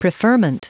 Prononciation du mot preferment en anglais (fichier audio)
Prononciation du mot : preferment